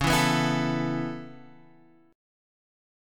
C#m7#5 chord